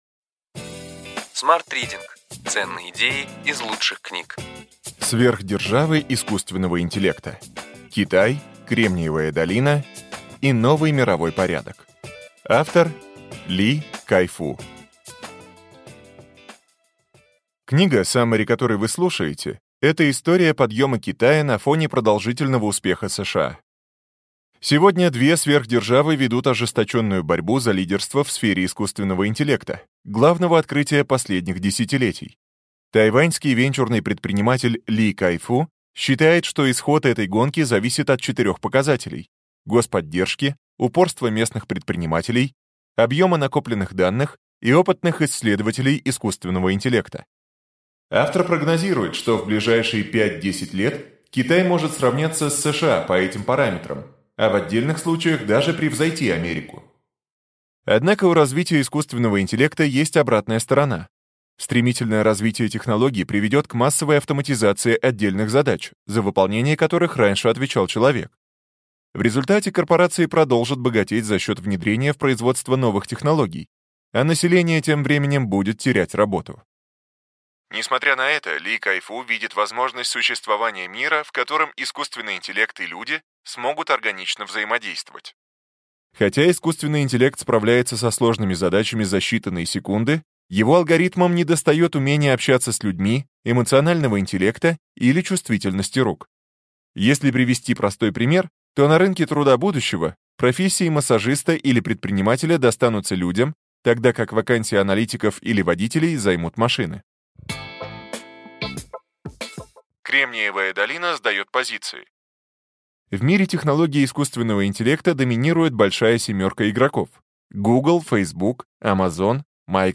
Аудиокнига Ключевые идеи книги: Сверхдержавы искусственного интеллекта: Китай, Кремниевая долина и новый мировой порядок.